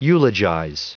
Prononciation du mot eulogize en anglais (fichier audio)
Prononciation du mot : eulogize
eulogize.wav